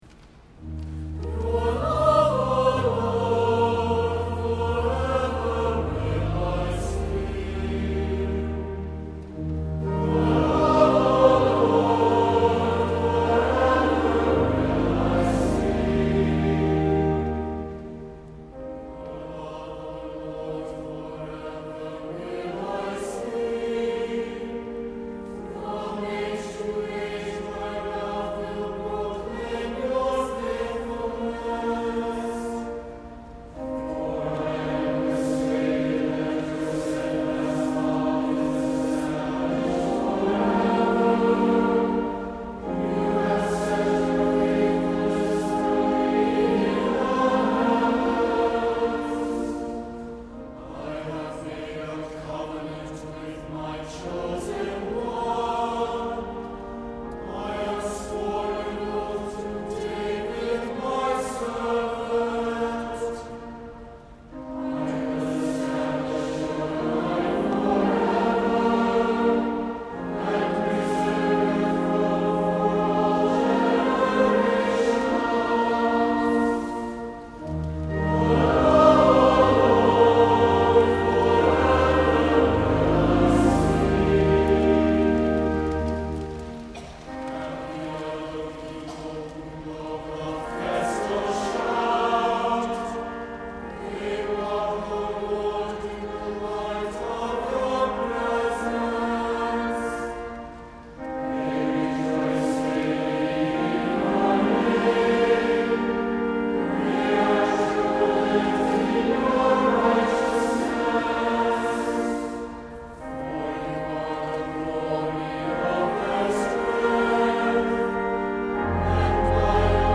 Responsive Psalm with Refrain and Choral ending SSAB